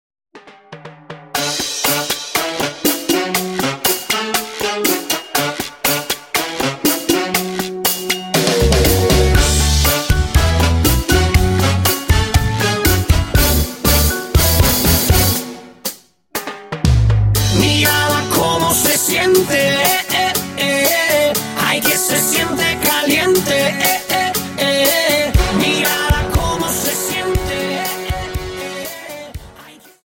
Cha Cha Song